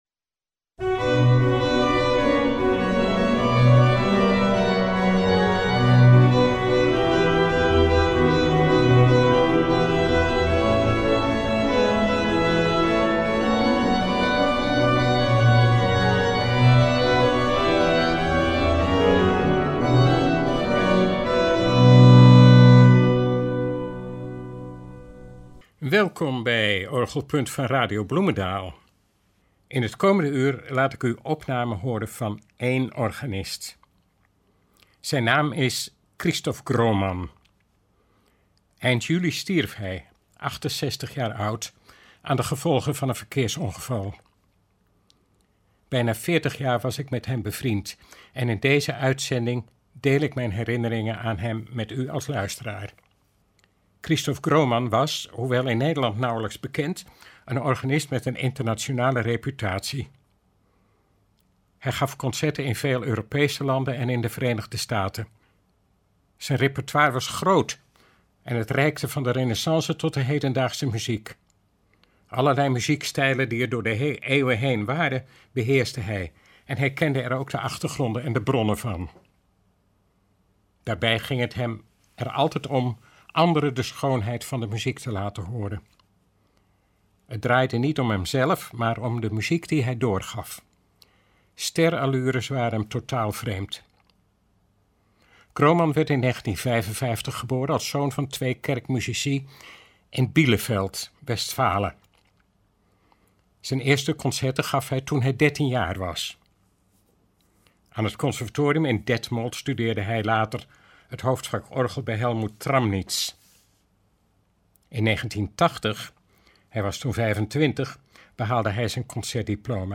Twee opnamen met improvisaties, gespeeld op orgels in Hasselt en IJsselmuiden illustreren dat.